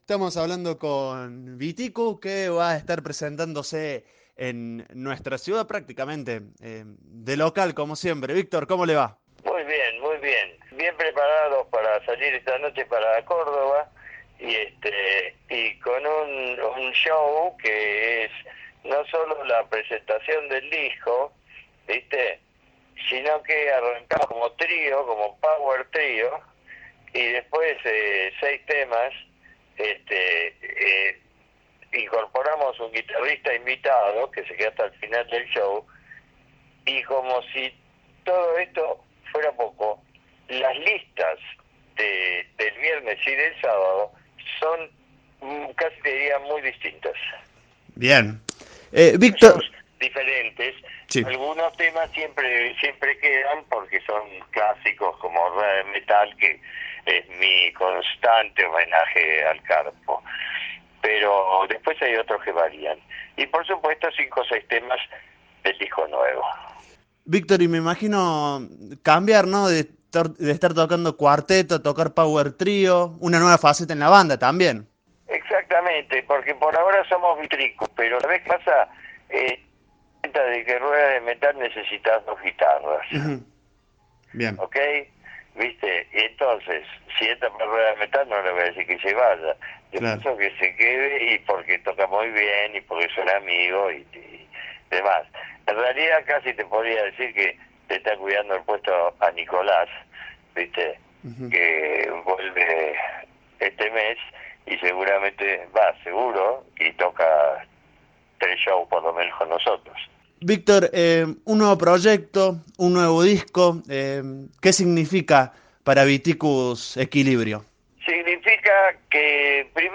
Entrevista-Viticus.mp3